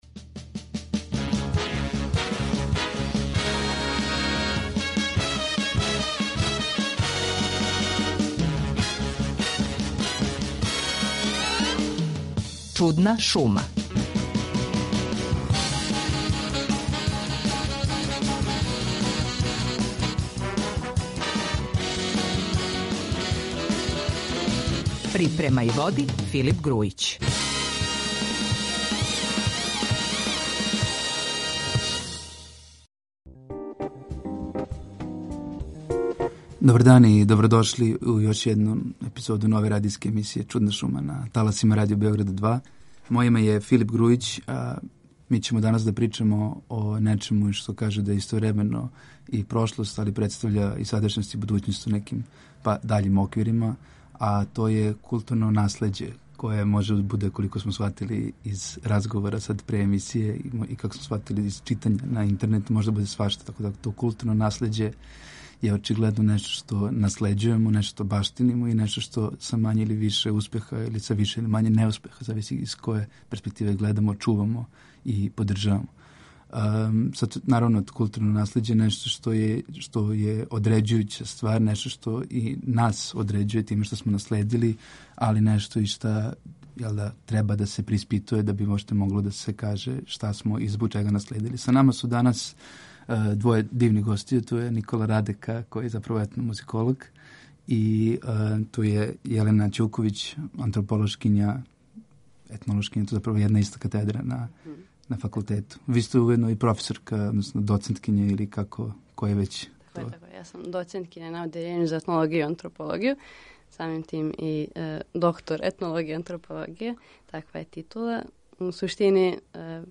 У новој епизоди емисије Чудна шума на таласима Радио Београда 2 причаћемо о нашем културном наслеђу, а гости ће бити